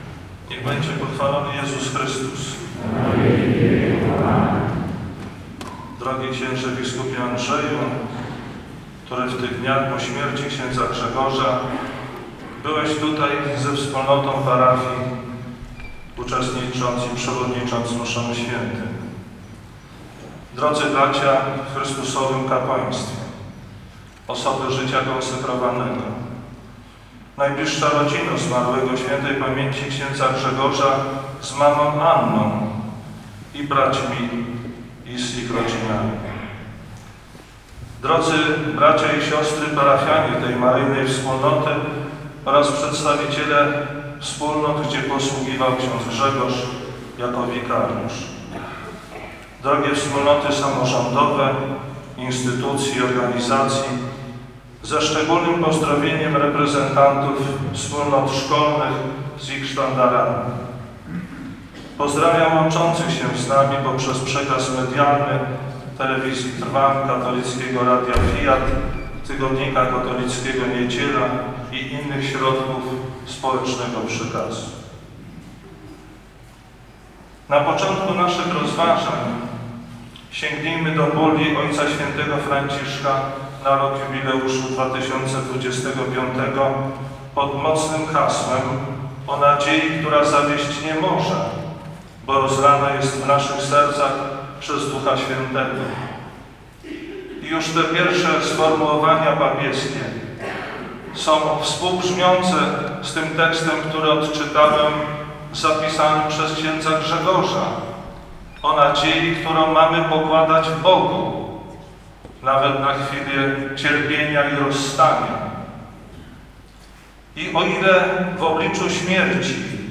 HOMILIA ABP. WACŁAWA (źródło: Radio Fiat).